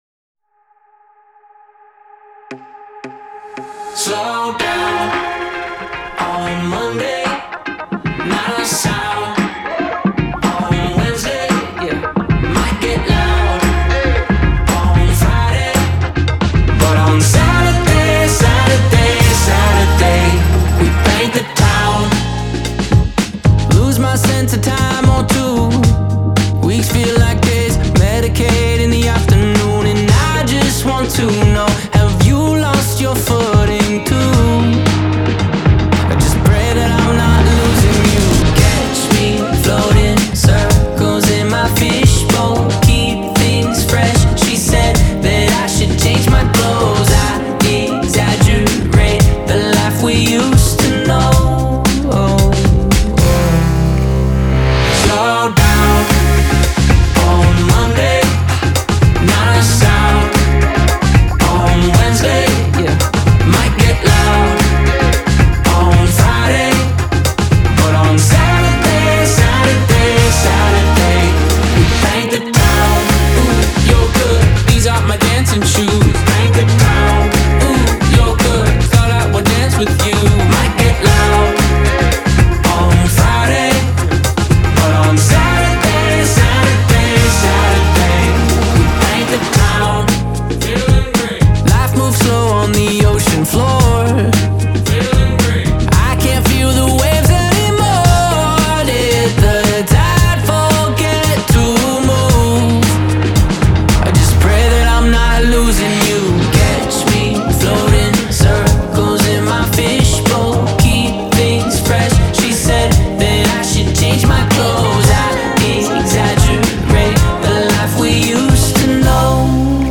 Genre : Indie Pop, Alternative